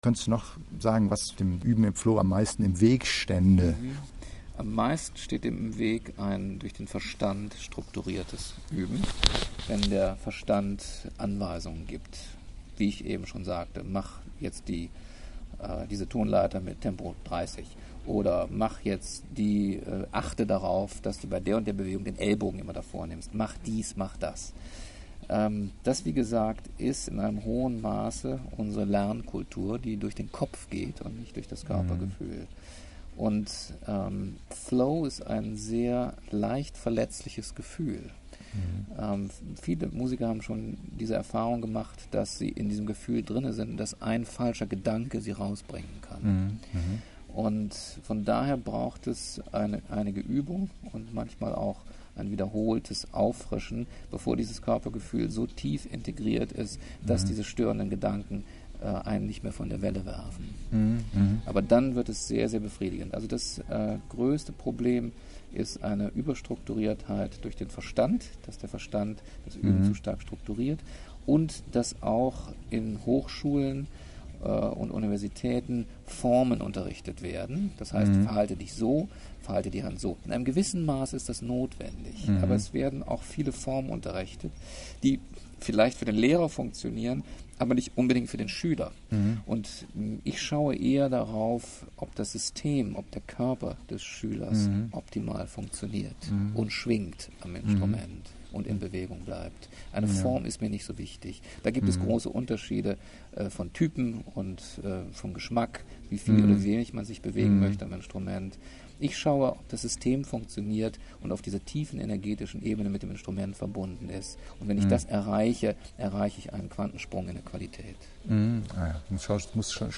Bericht vom Seminar & Audio Interview